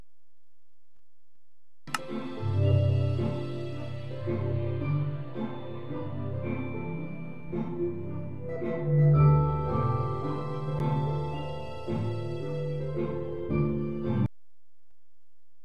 Again, you can hear multiple songs playing at once.